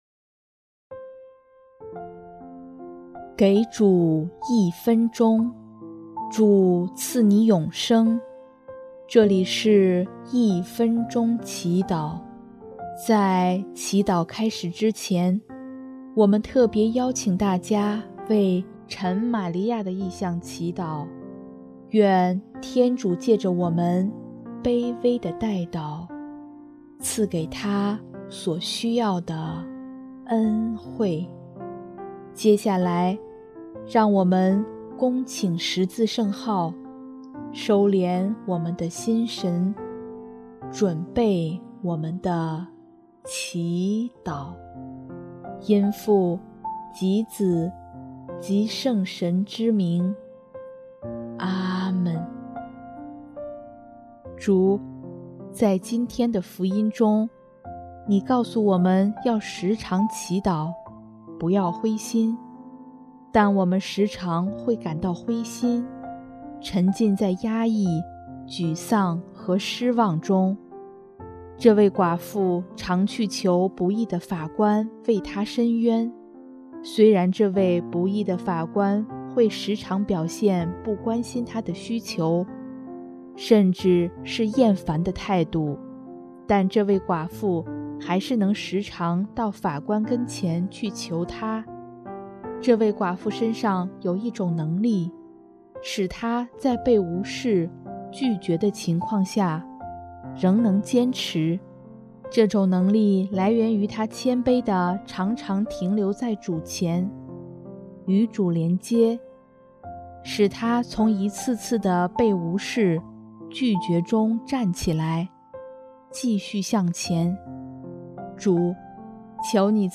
【一分钟祈祷】|10月19日 主，求你赐予我们更多的信靠
音乐： 主日赞歌《信德的祈祷》